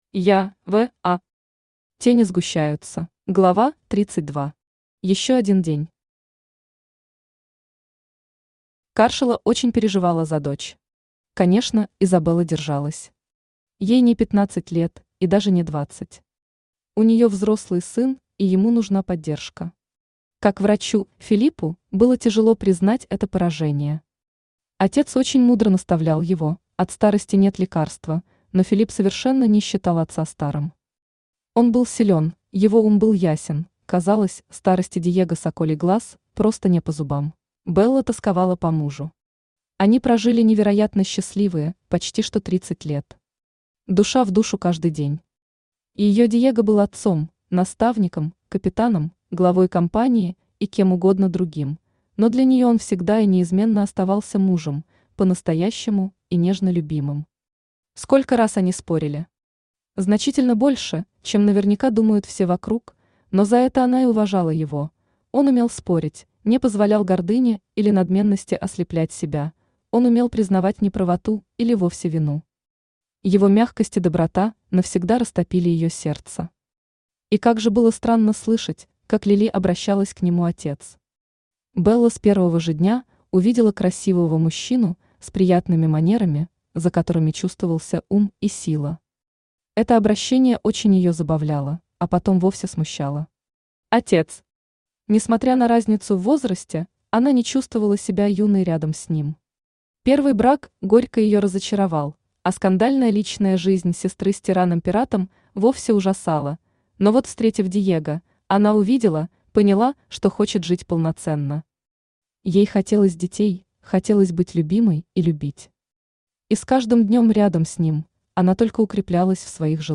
Аудиокнига Тени сгущаются | Библиотека аудиокниг
Aудиокнига Тени сгущаются Автор Я.В.А. Читает аудиокнигу Авточтец ЛитРес.